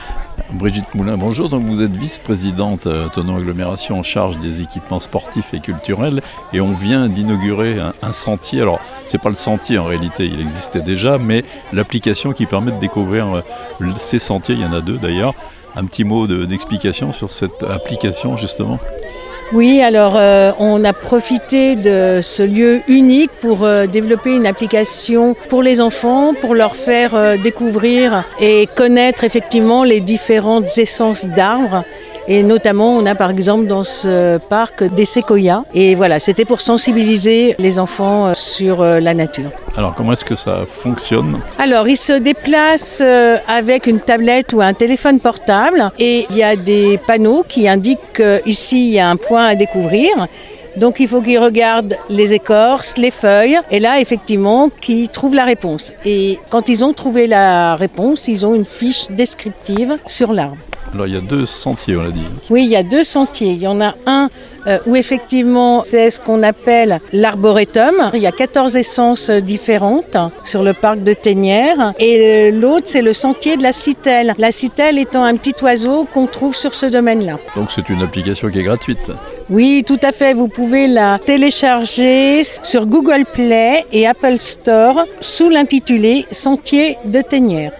Brigitte Moulin, Vice-présidente de Thonon Agglomération, chargée des politiques culturelles et sportives présente cette nouvelle application